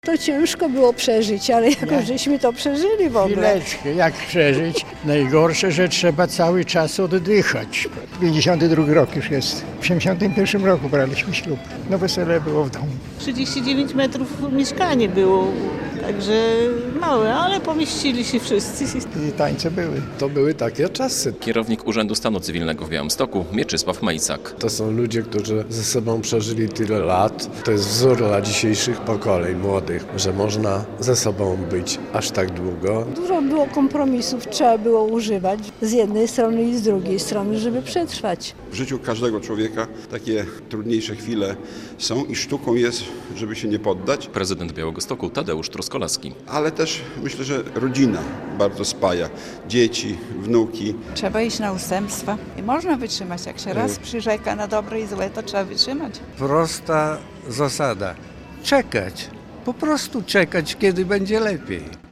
Podczas uroczystości w Pałacu Branickich medale zasłużonym parom wręczał prezydent Białegostoku Tadeusz Truskolaski.